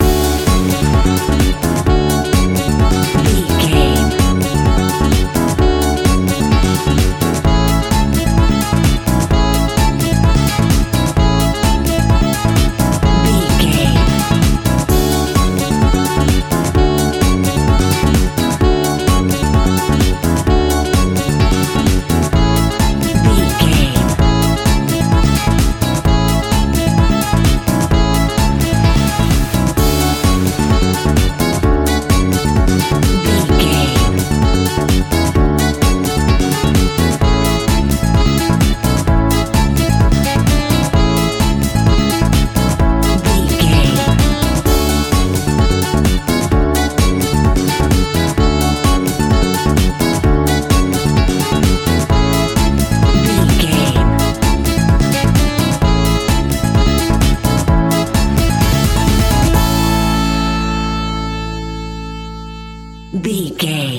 Ionian/Major
groovy
uplifting
bouncy
electric guitar
bass guitar
drums
synthesiser
saxophone
upbeat
wah clavinet